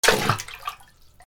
/ M｜他分類 / L30 ｜水音-その他
水に沈める 3
『ジュポン』